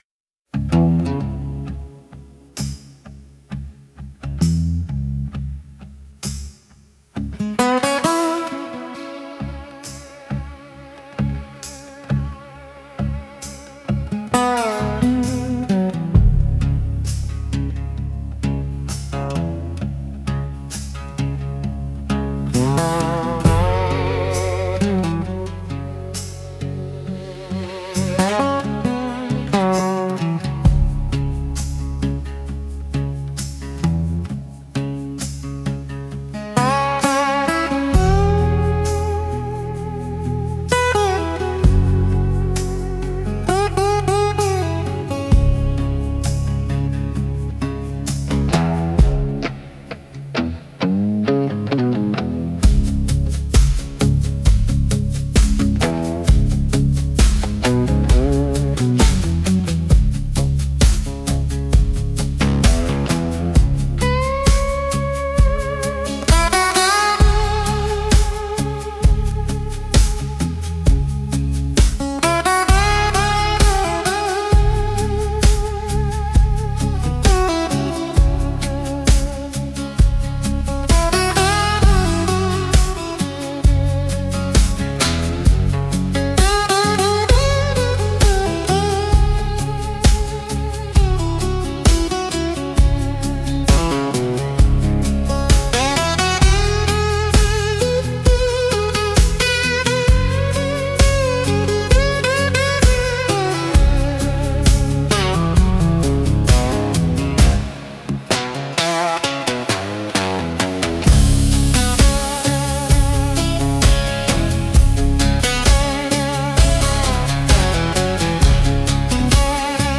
Instrumental - Sliding into Shadow